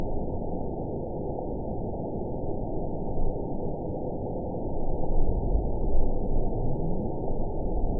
event 919886 date 01/27/24 time 22:20:04 GMT (1 year, 4 months ago) score 9.29 location TSS-AB07 detected by nrw target species NRW annotations +NRW Spectrogram: Frequency (kHz) vs. Time (s) audio not available .wav